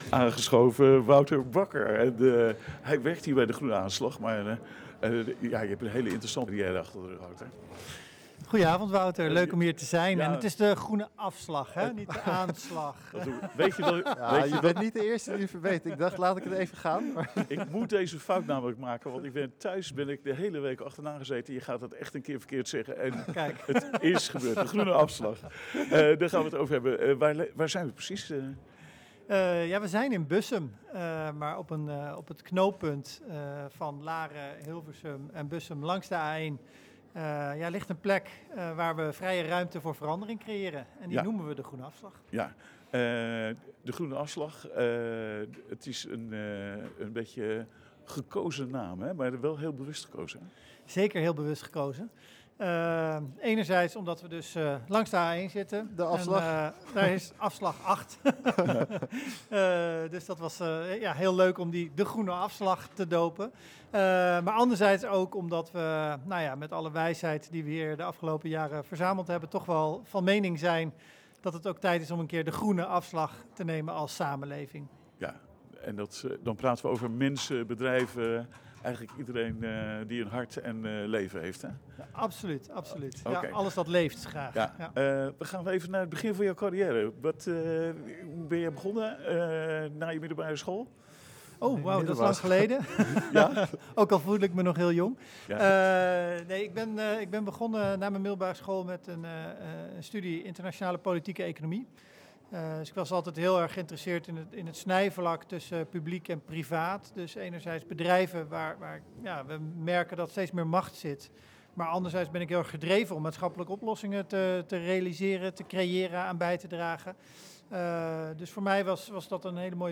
Luister naar dit deelinterview opgenomen tijdens de liveuitzending van 17 november 2025.